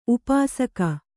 ♪ upāsaka